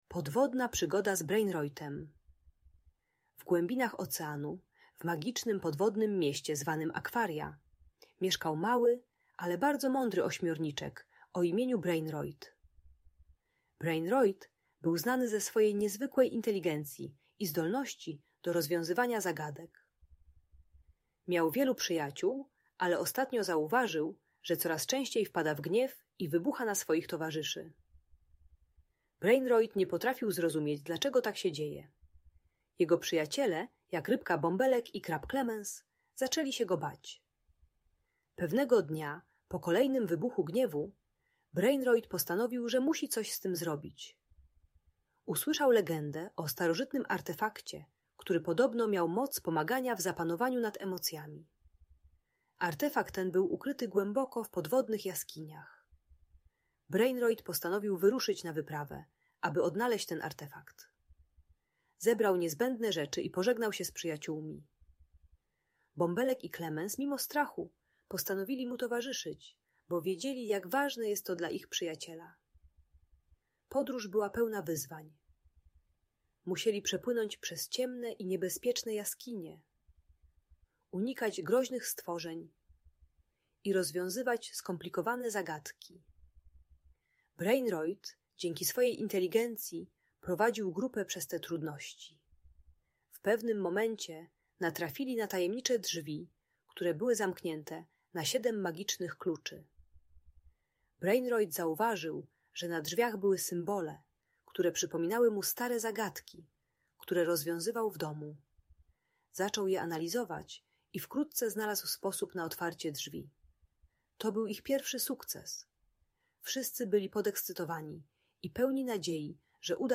Podwodna Przygoda z Brainroitem - Bunt i wybuchy złości | Audiobajka